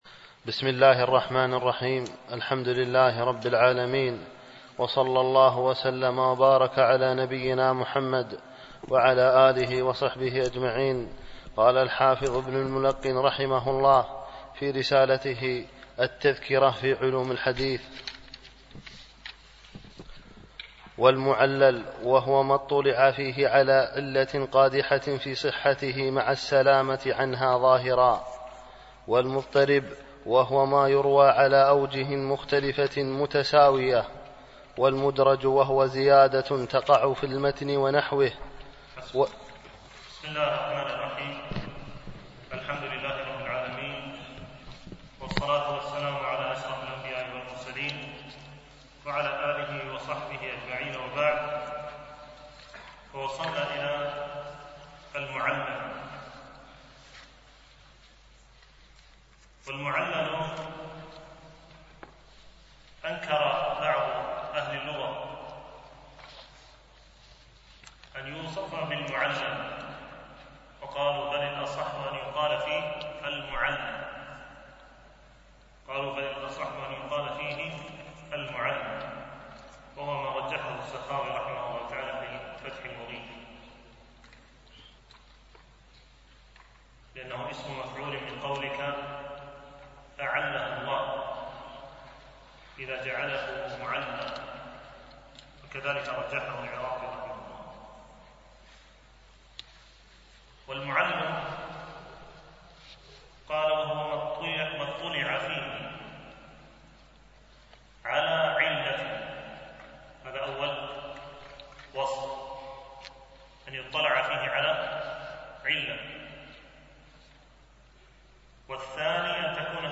دورة الإمام مالك العلمية الثانية بدبي (10-13 رجب 1436هـ)
دروس مسجد عائشة (برعاية مركز رياض الصالحين ـ بدبي) المدة